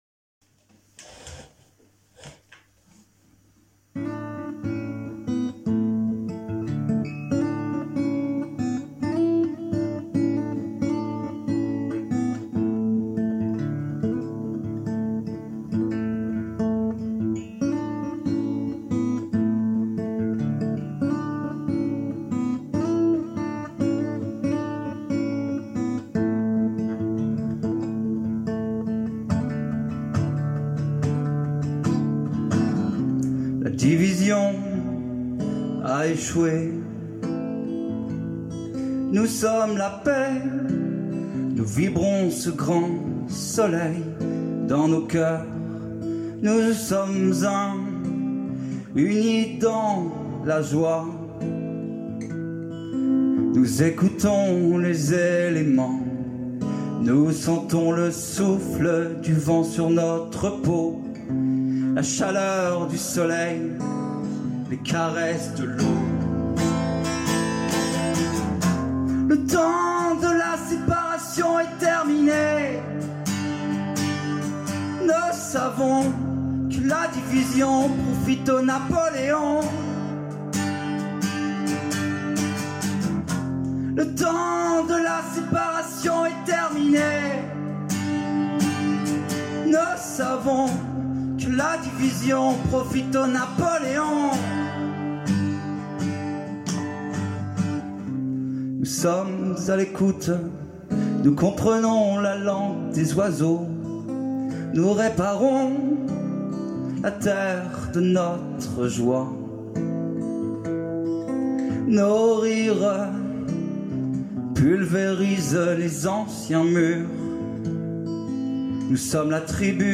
Mis en musique et interprété